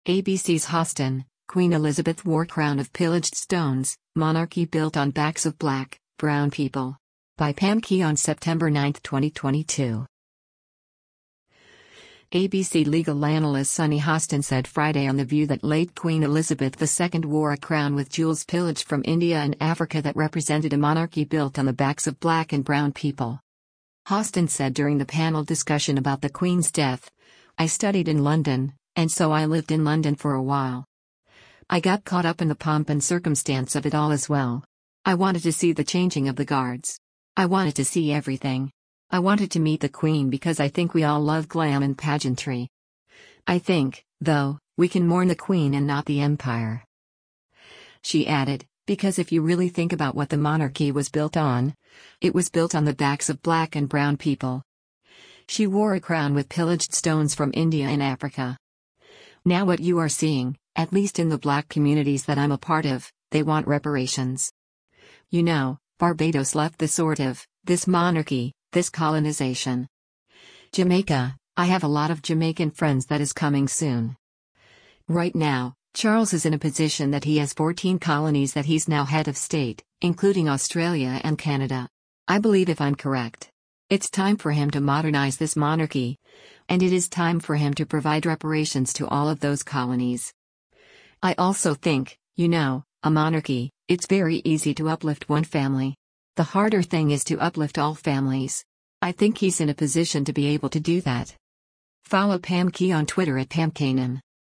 ABC legal analyst Sunny Hostin said Friday on “The View” that late Queen Elizabeth II wore a crown with jewels “pillaged from India and Africa” that represented a monarchy “built on the backs of black and brown people.”